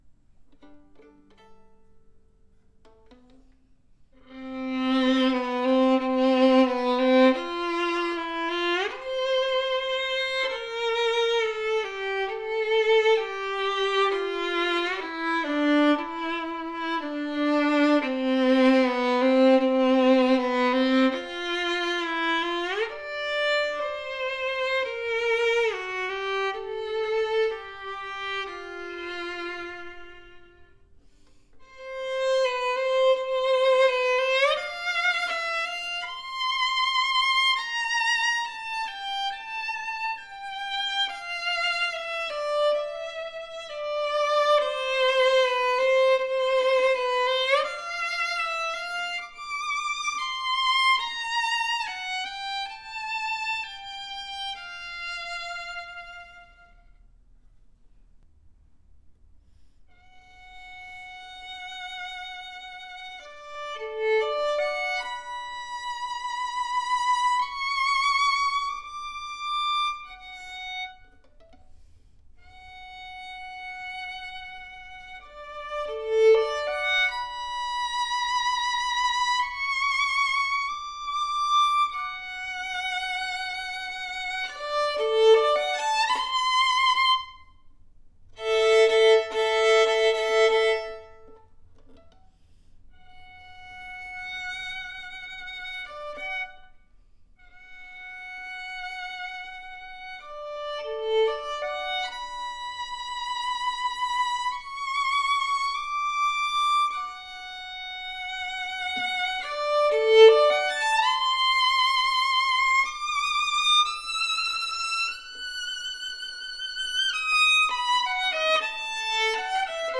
A RARE fine sounding violin at this price range, special edition made after the Gagliano pattern, that delivers a superior WARM SWEET and PROJECTIVE tone, more tone than you would expect at this price range!! Exquisite antique golden brown varnish, full and extra higher arching creates a powerful tone with deep and bold projection. A vibrant, sweet sounding violin that built with seriousness, our most affordable instrument that represents super value for violin players.